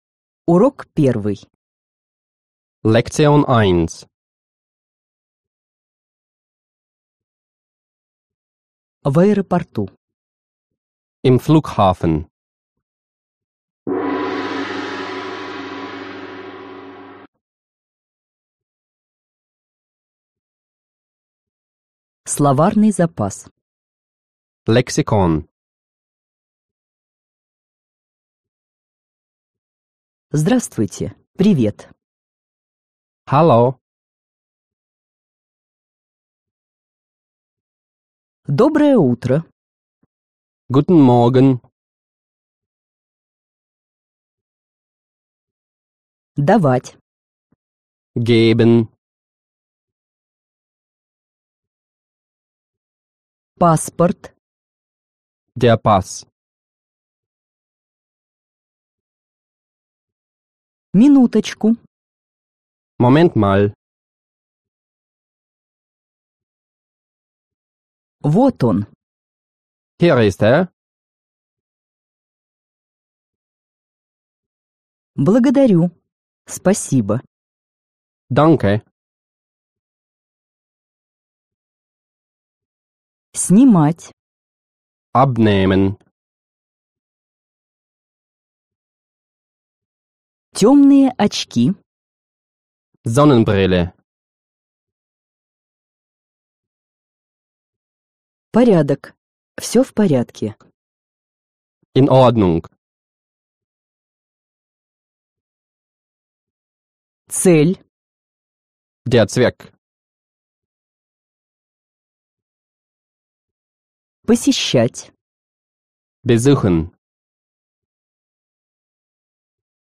Аудиокнига Немецкий язык за 2 недели | Библиотека аудиокниг
Aудиокнига Немецкий язык за 2 недели Автор Коллектив авторов Читает аудиокнигу Профессиональные дикторы.